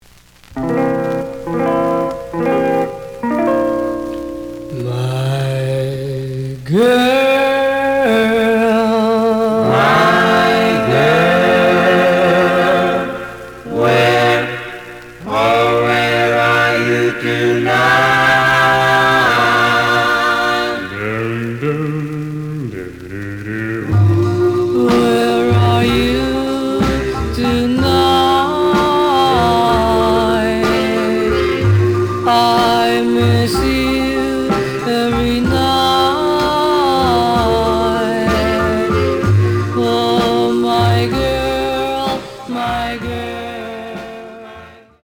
●Genre: Rhythm And Blues / Rock 'n' Roll
Some click noise on both sides.